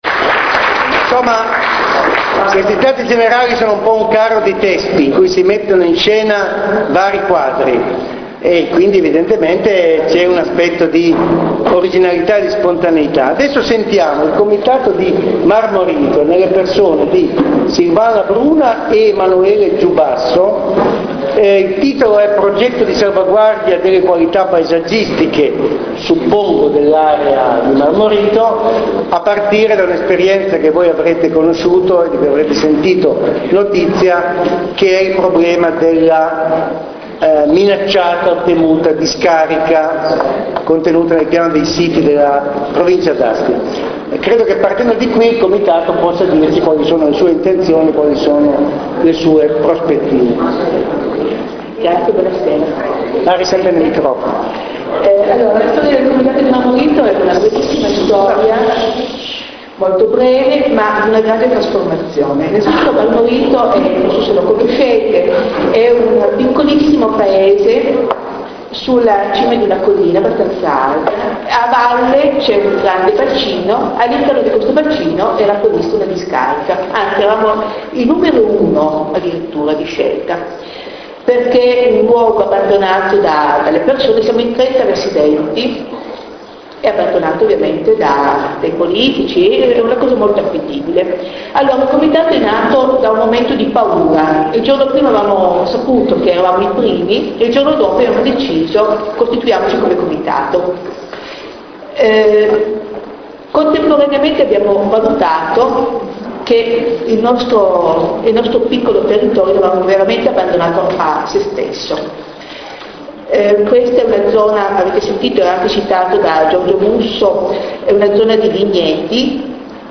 Resoconto Stati generali del Paesaggio astigiano a Moncucco Torinese (21 giugno 2008)
Relazione